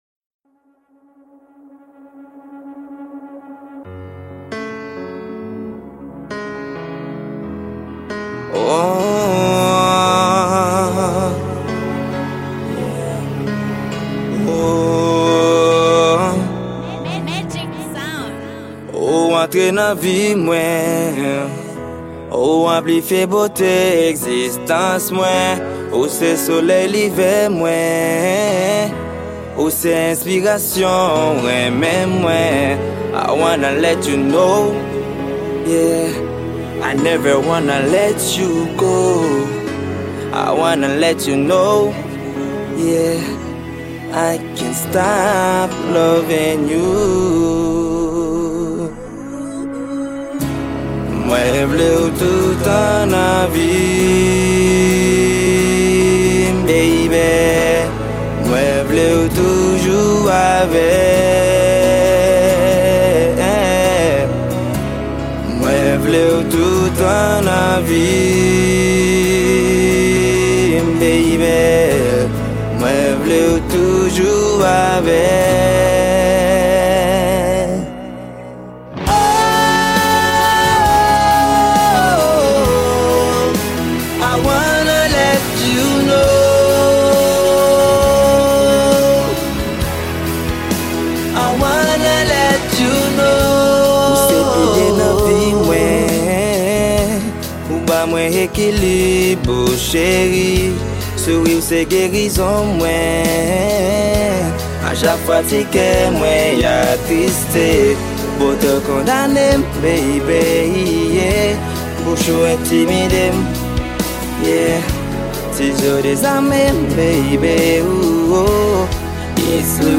Genre : WORLD